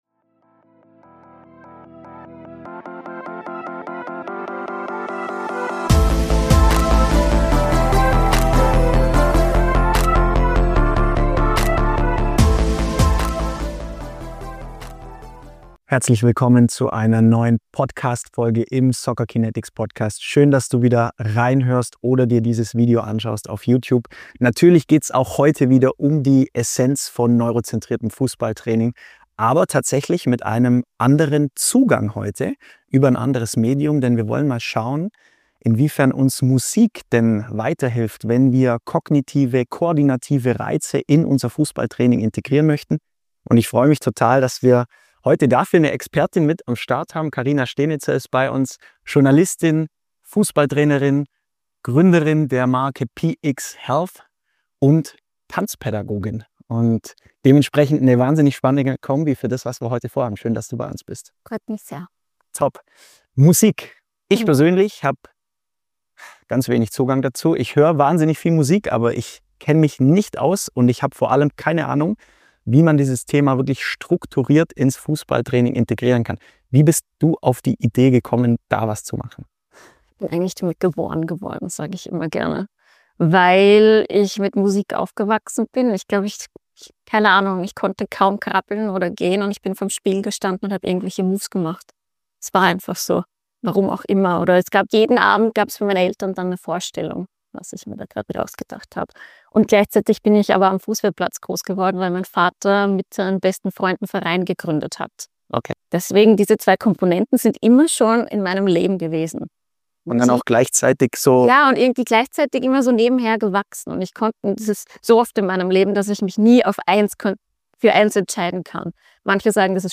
Gemeinsam sprechen sie über die Wirkung dieses Ansatzes auf Entscheidungsfindung, Mut und Spielfluss.